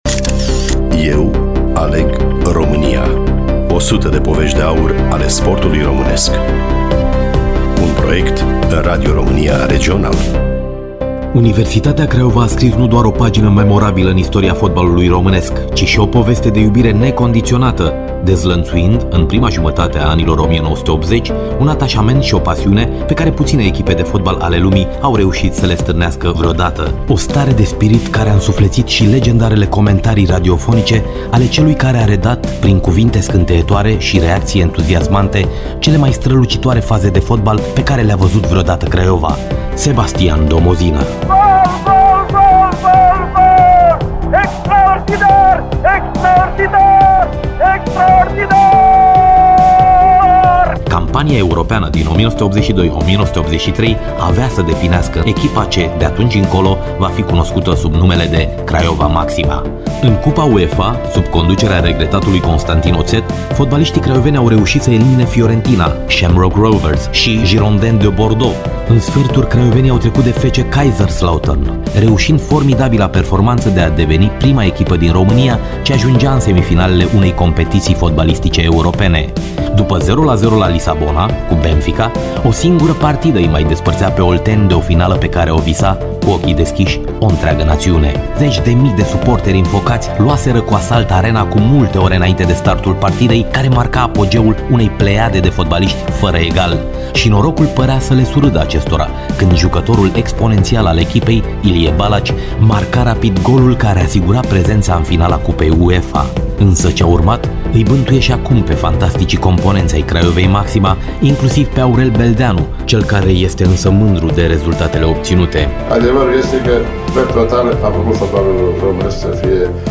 Cum s-a construit acea echipă de vis şi cum s-a născut acea stare de graţie în Bănie îşi aminteşte Aurel Beldeanu:
Studioul Radio România Oltenia Craiova